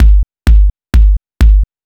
VTDS2 Song Kit 128 BPM Rap 1 Out Of 2
VTDS2 Song Kit 04 Rap 1 Out Of 2 Kick.wav